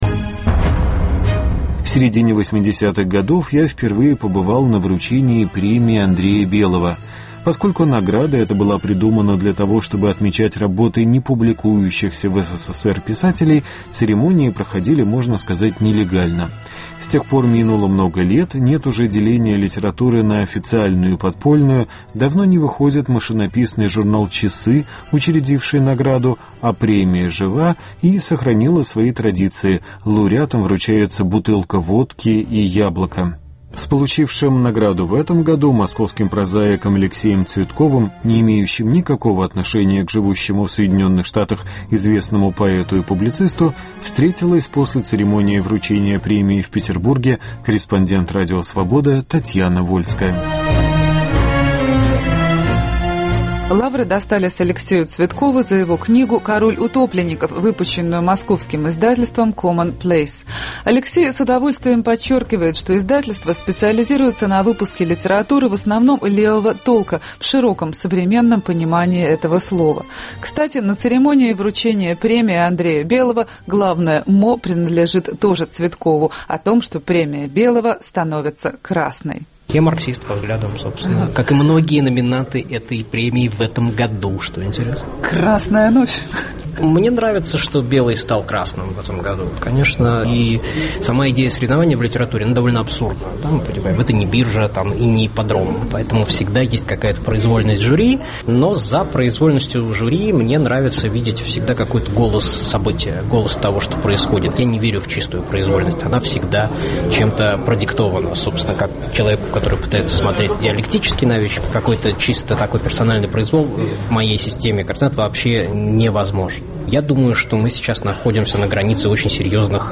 Разговор писателем с Алексеем Цветковым.